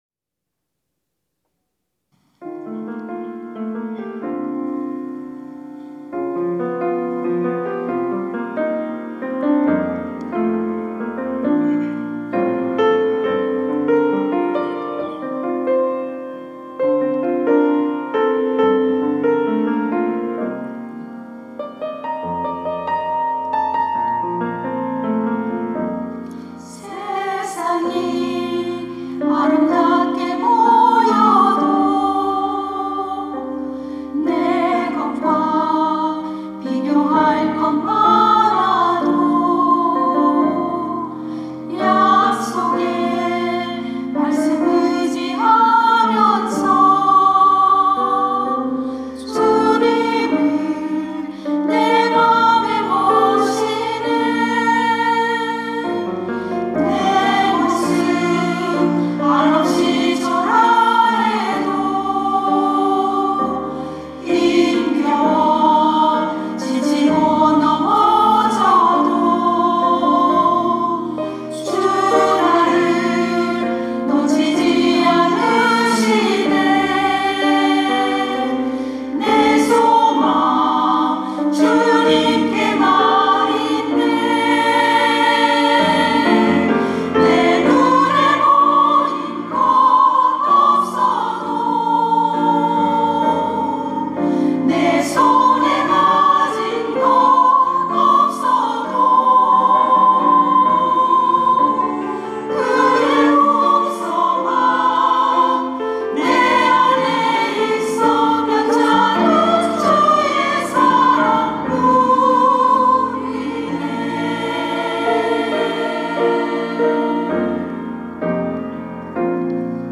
특송과 특주 - 회복
권사 합창단